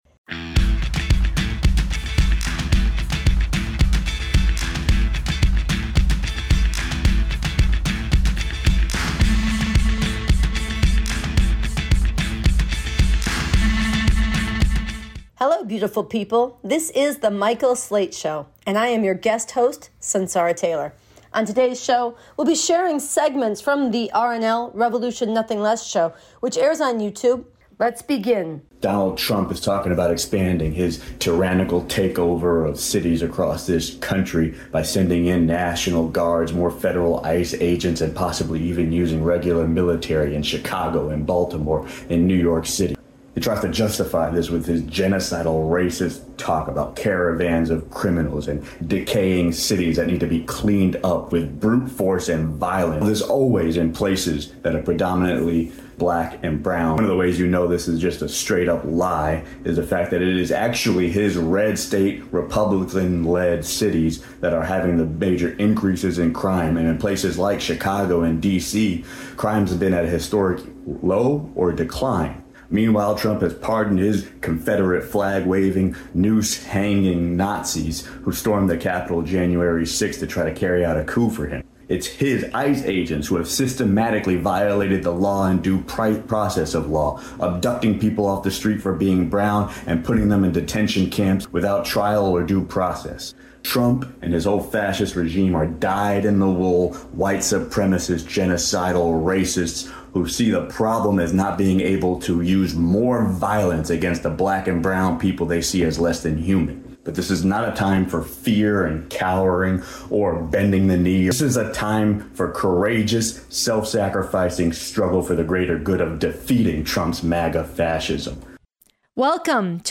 Voices from Los Angeles, protest for the disappeared.